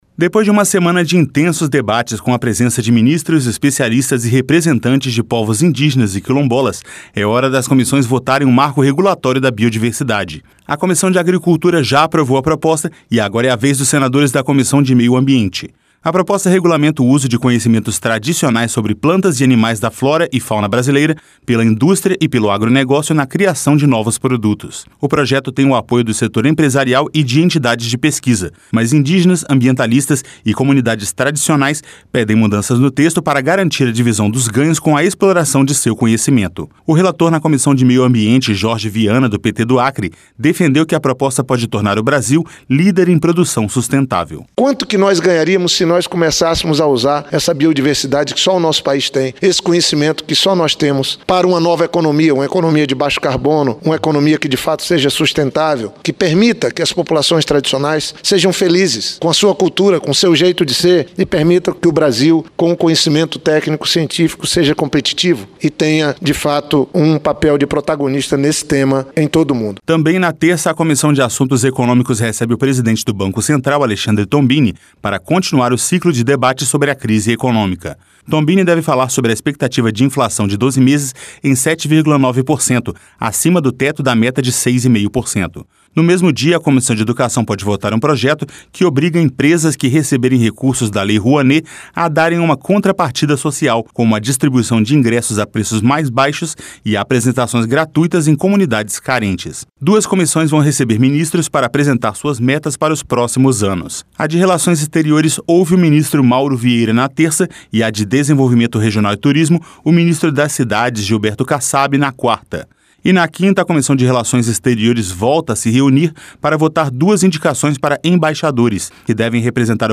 O relator na Comissão de Meio Ambiente, Jorge Viana, do PT do Acre, defendeu que a proposta pode tornar o Brasil líder em produção sustentável.